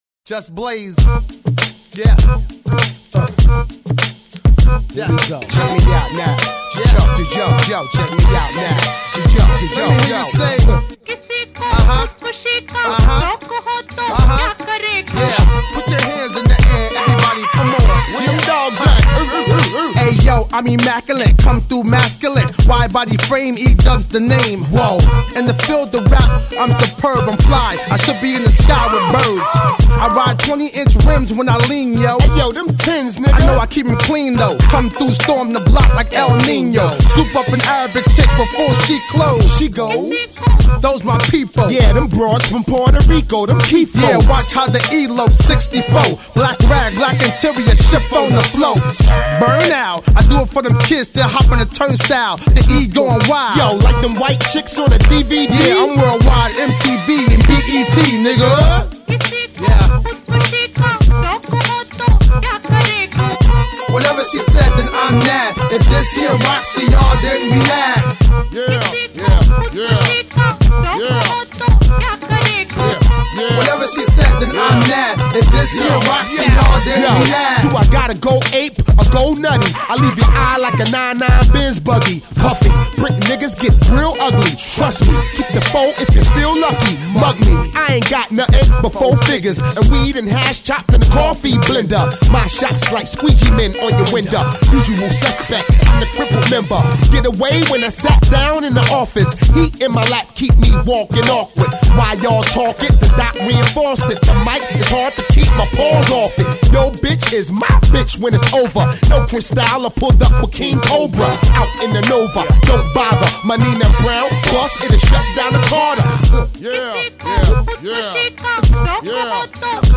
Назад в (rap)...
Файл в обменнике2 Myзыкa->Рэп и RnВ